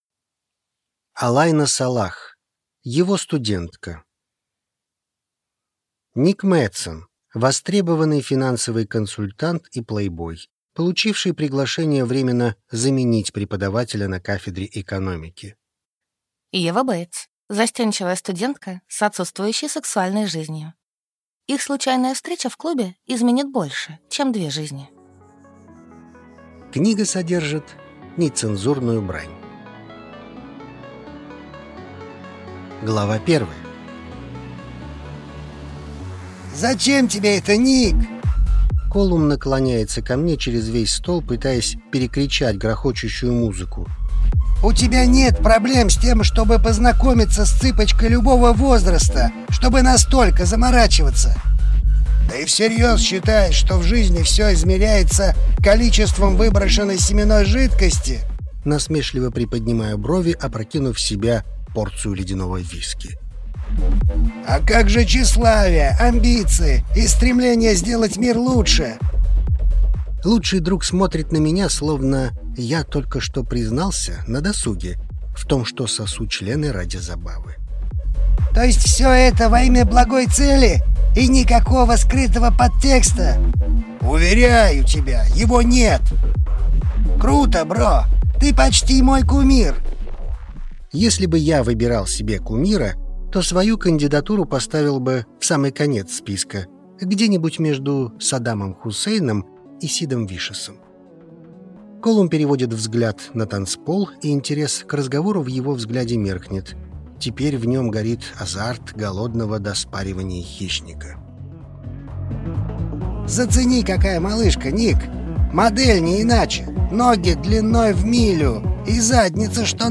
Аудиокнига Его студентка | Библиотека аудиокниг
Прослушать и бесплатно скачать фрагмент аудиокниги